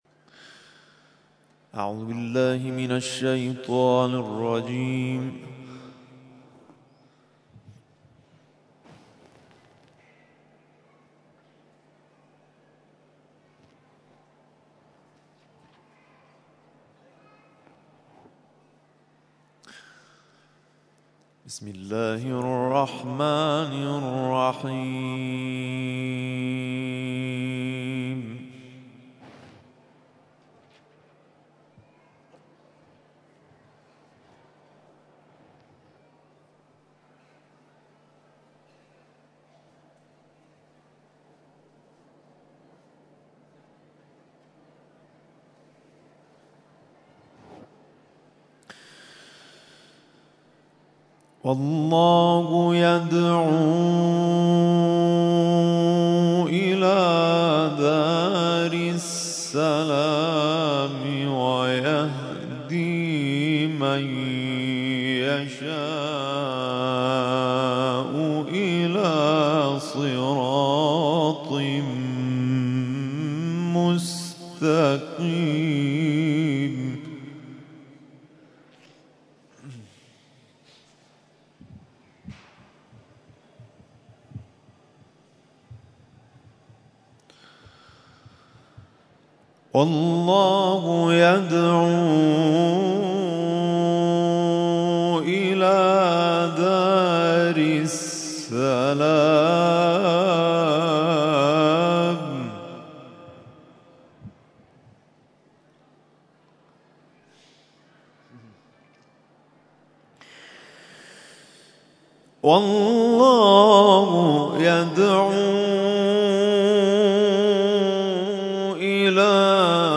تلاوت قرآن کریم